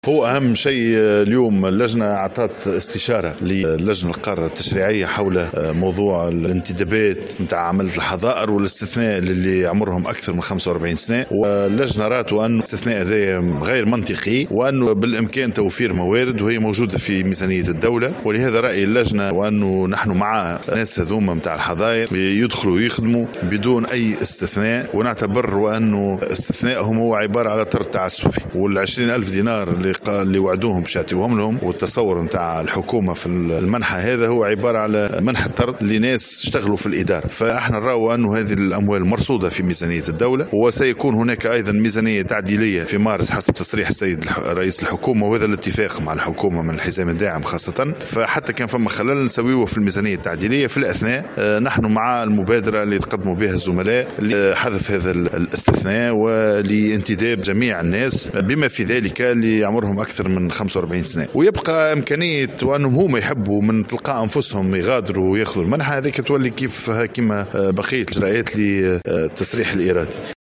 كما بين اللومي في تصريح